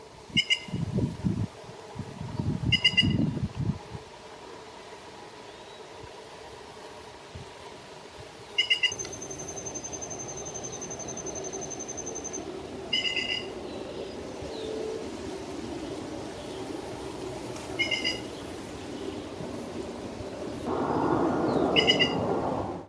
Plush-crested Jay (Cyanocorax chrysops)
Escuchada repetidamente en la zona de bosque de la reserva.
Condition: Wild
Certainty: Observed, Recorded vocal